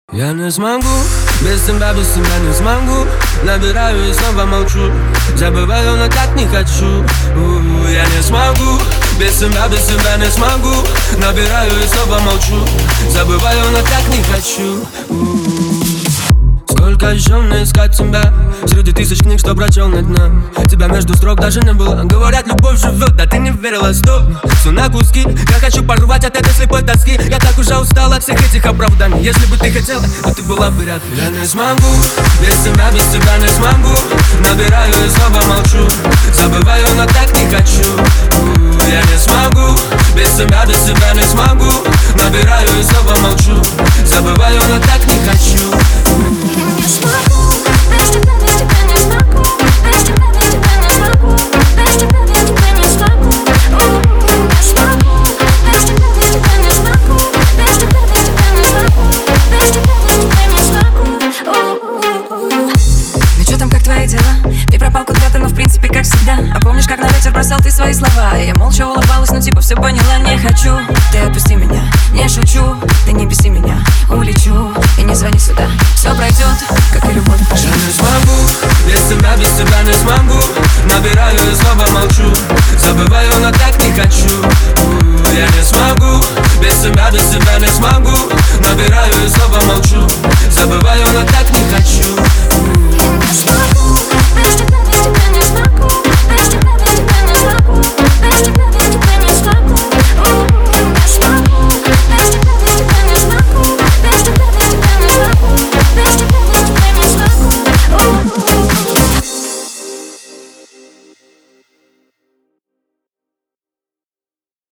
это современная поп-музыка с элементами EDM